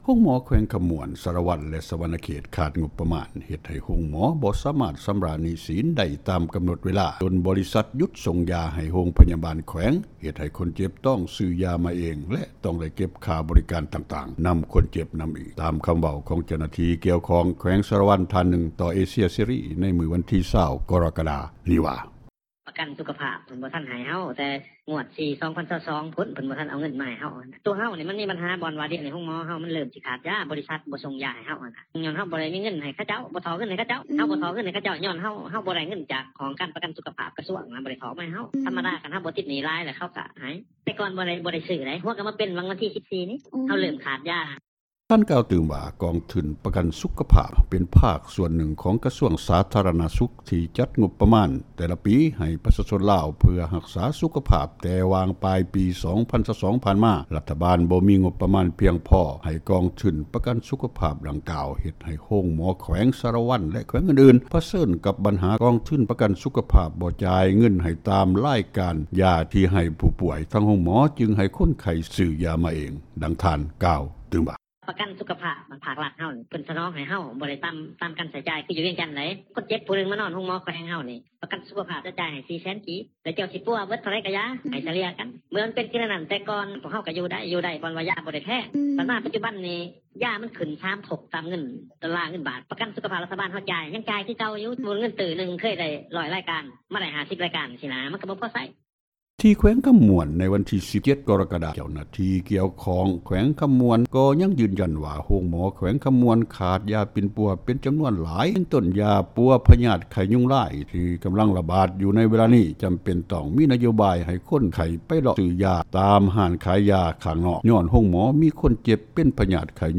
ນັກຂ່າວ ພົລເມືອງ
ດັ່ງຍານາງເວົ້າວ່າ: